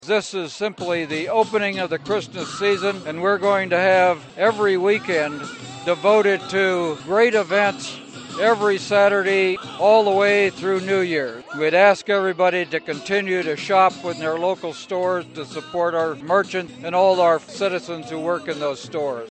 KMAN spoke with Manhattan City Mayor Mike Dodson after the lighting to give his comments on the event.